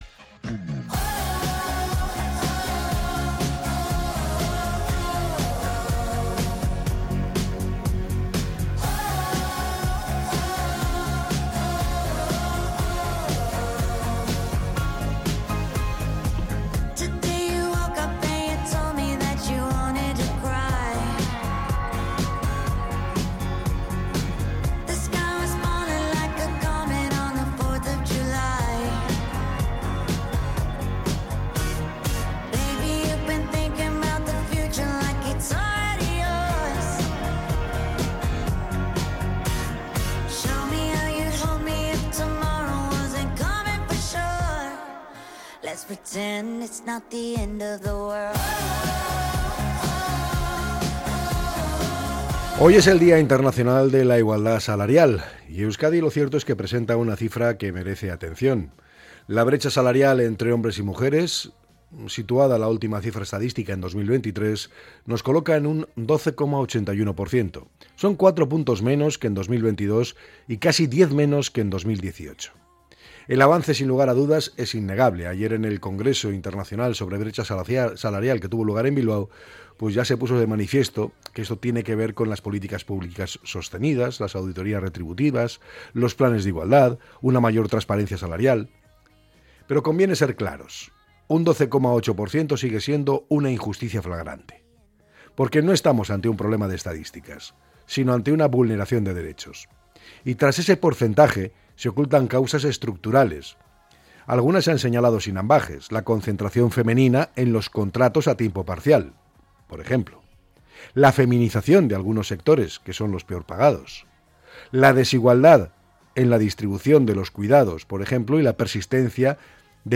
El comentario